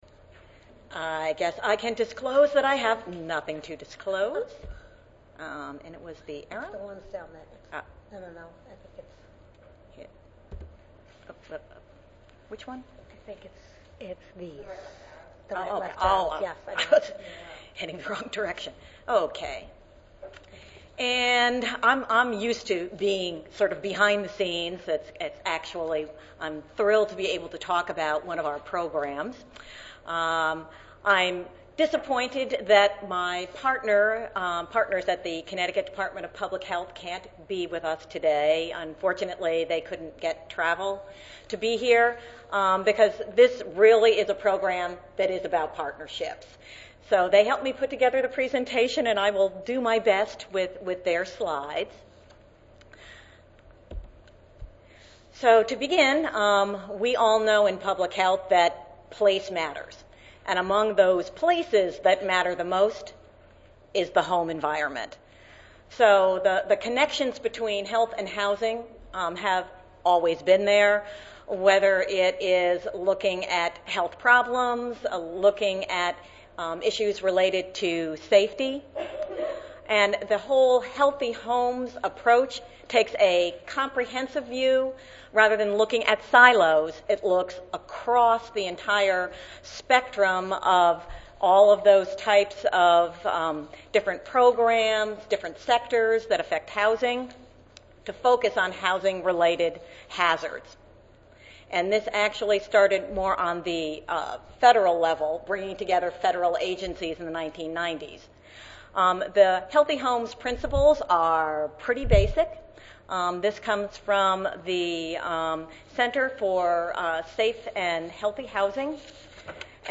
Five different Health Resources and Services Administration (HRSA) funded Public Health Training Centers (PHTCs) around the country will share different programs and experiences relating to the education of public health professionals in areas that are of interest to their particular niche of workers. The session will demonstrate innovative and creative ways of addressing public health issues.